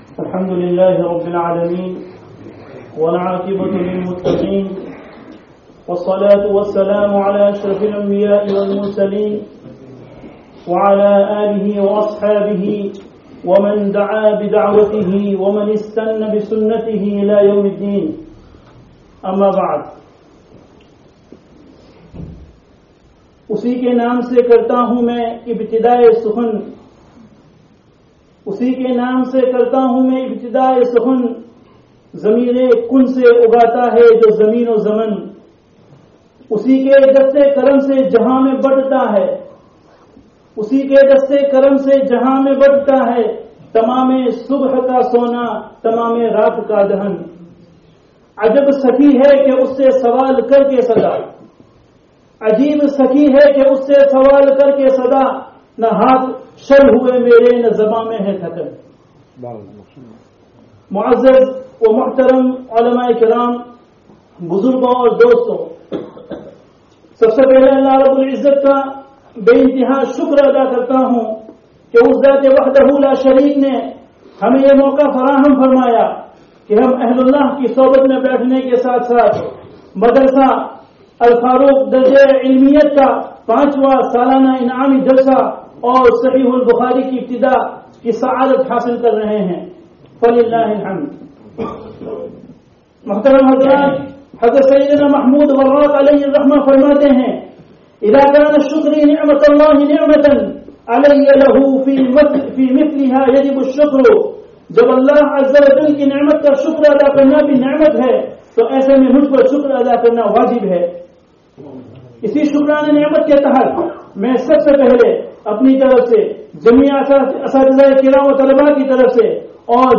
Iftitāhe Bukhārī (Masjid al Farooq, Walsall 19/01/19)